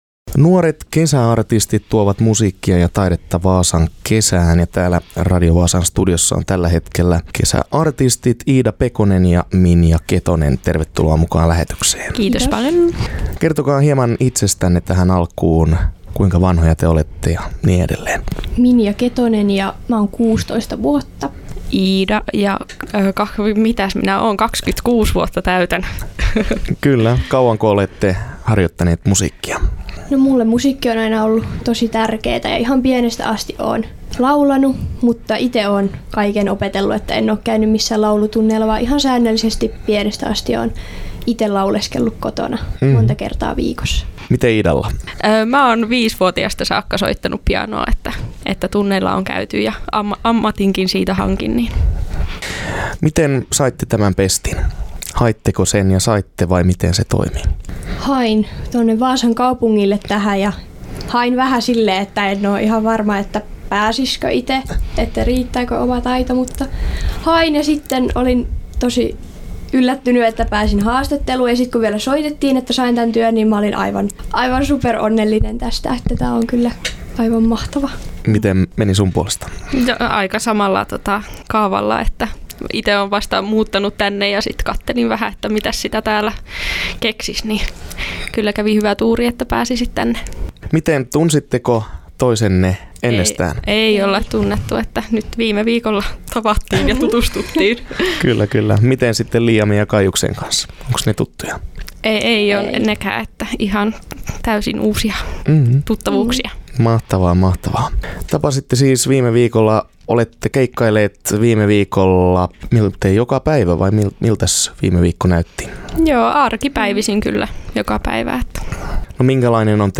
Vaasan kaupungin kesäartistit vieraili studiolla / Vasa stads sommarartister gästade studion